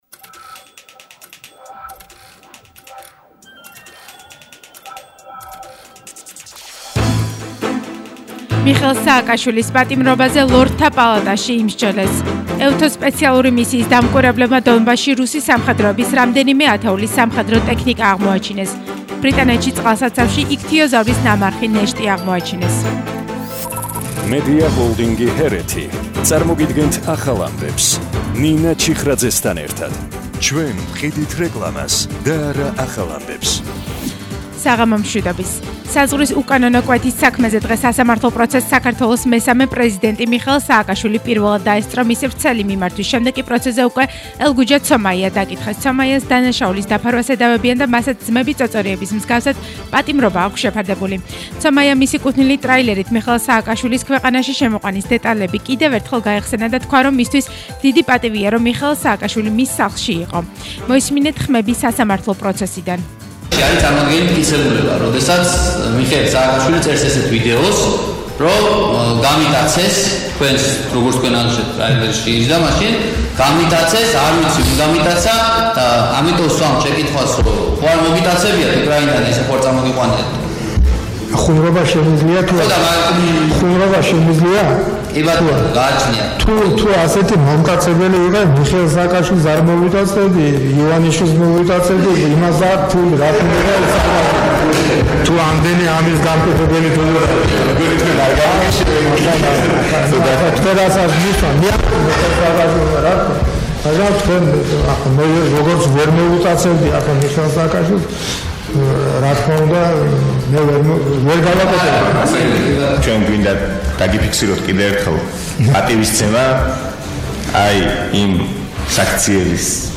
ახალი ამბები 19:00 საათზე – 13/01/22